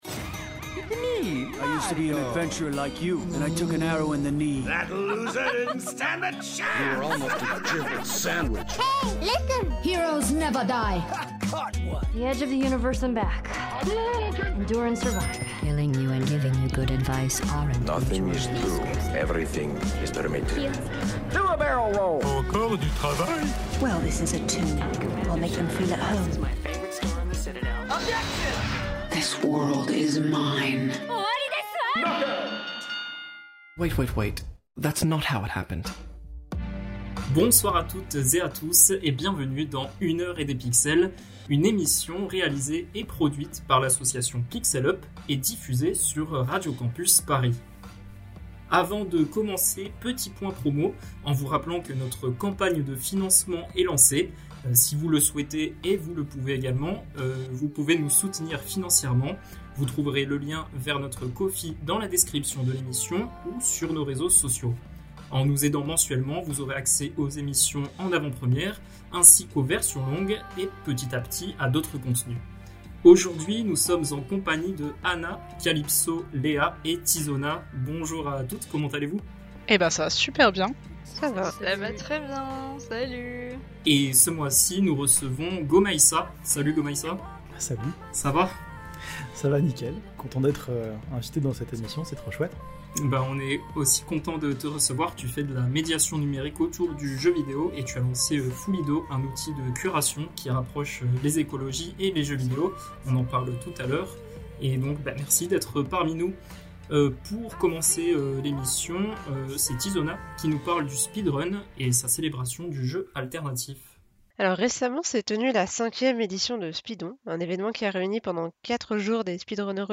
Émission diffusée le 22 mars 2025 sur Radio Campus Paris.